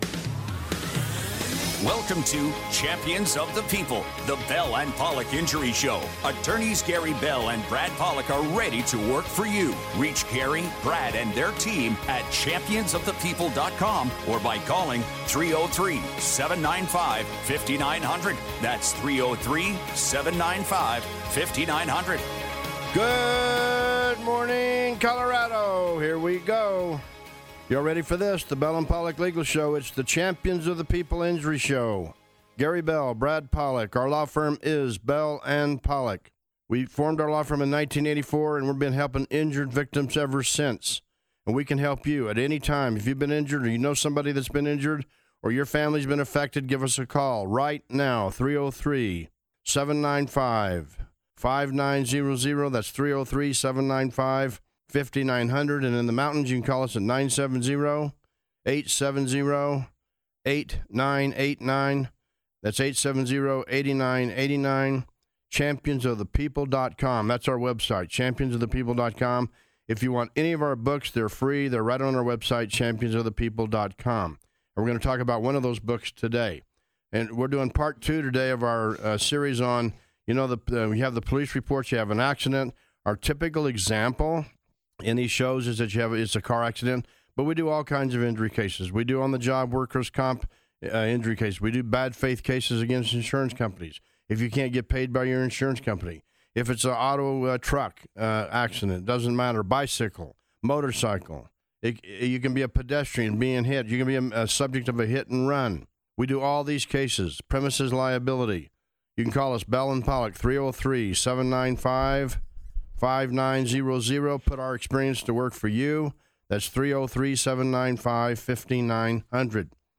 champions-of-the-people-koa-broadcast-example.mp3